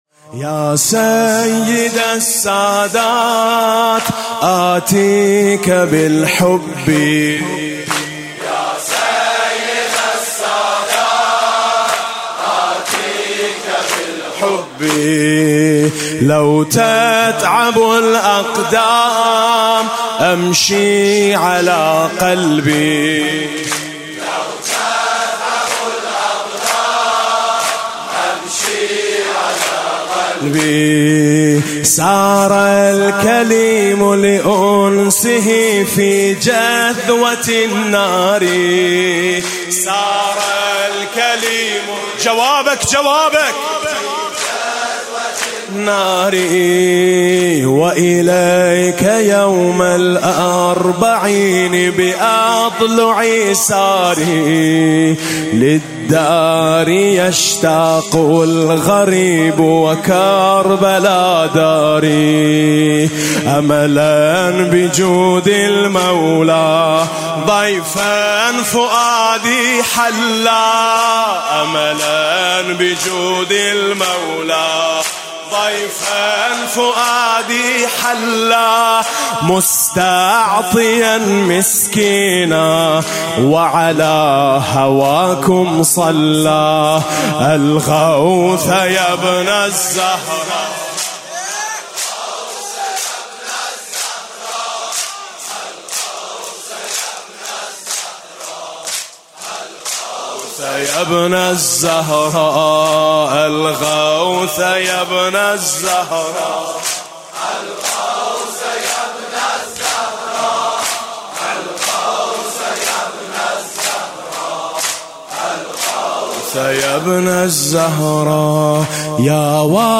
عمود ۷۲۸ - مداحی عربی